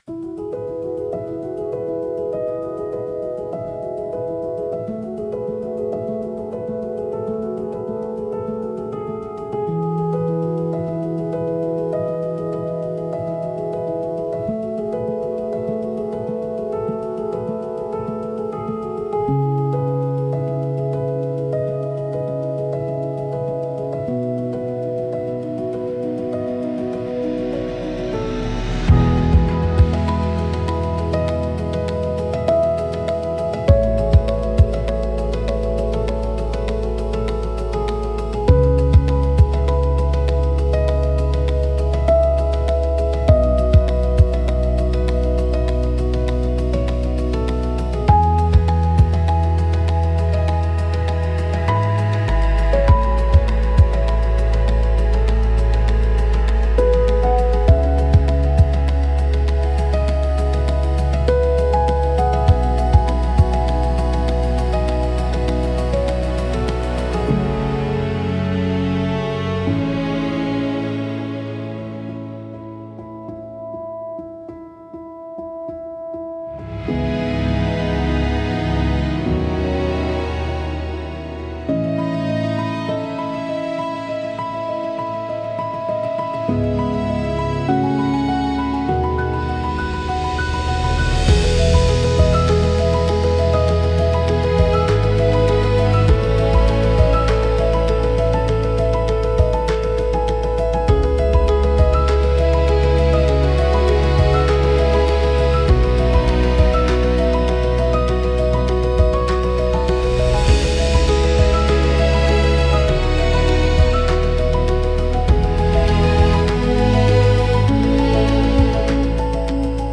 Sound Design-Audio Assets